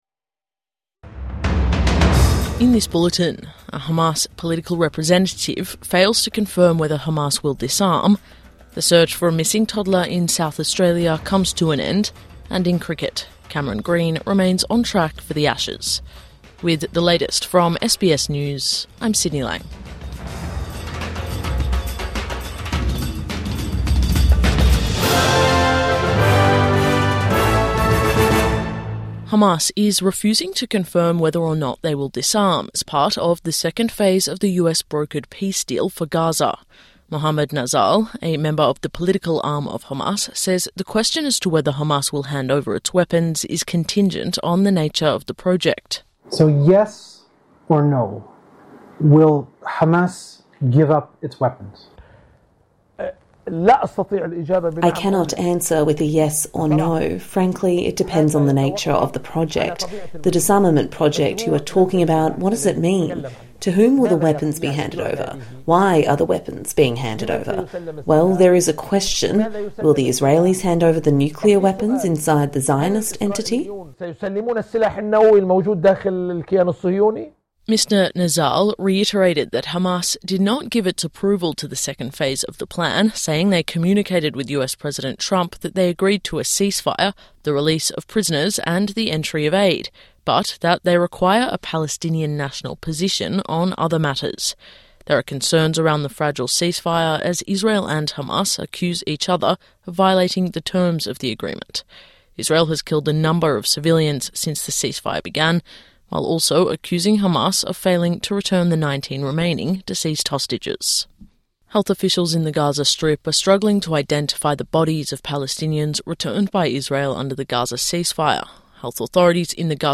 Morning News Bulletin 18 October 2025